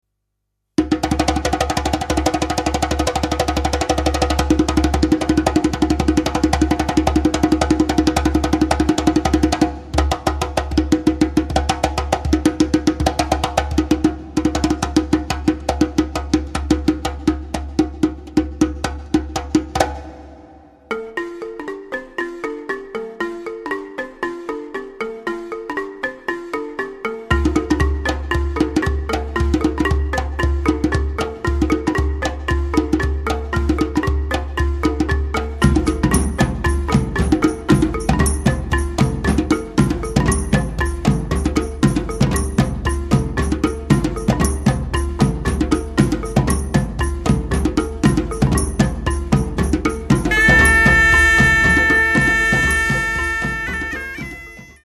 Schwegel und Rauschpfeifen
Djembe